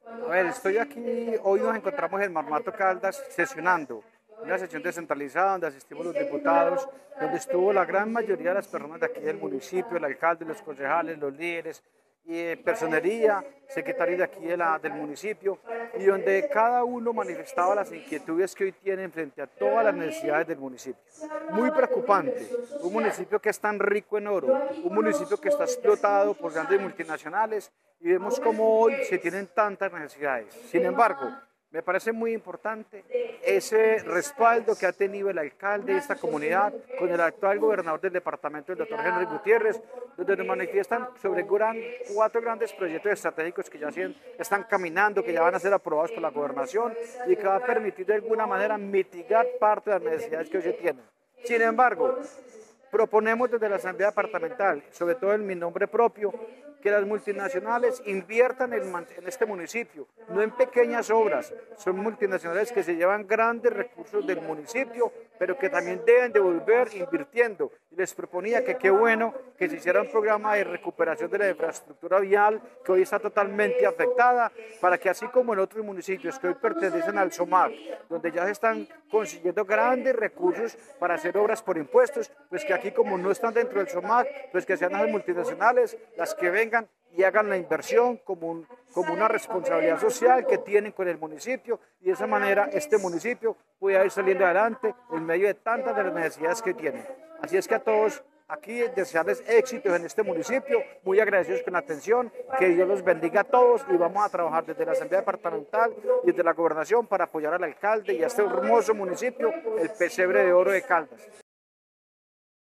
Diputado de Caldas, Jahir de Jesús Álvarez.